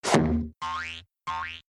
teleport_reappear.ogg